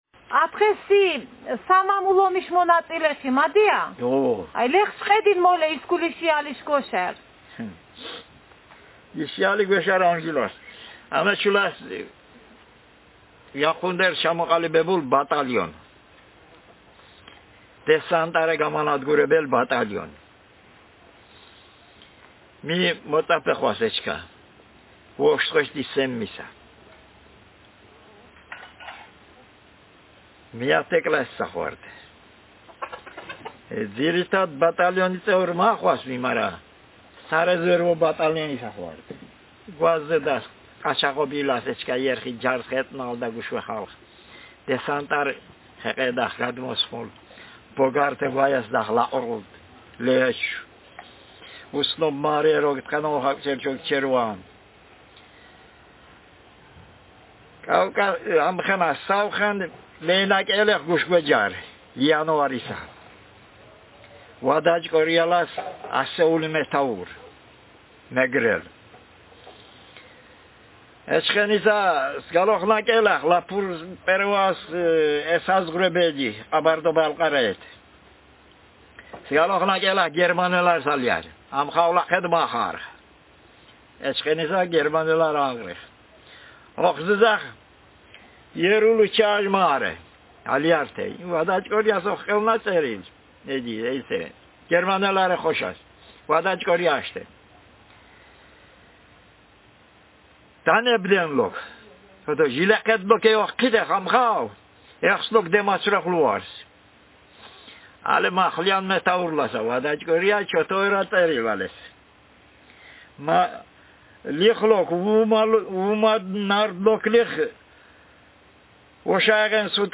Speaker age70
Speaker sexm
Text genrepersonal narrative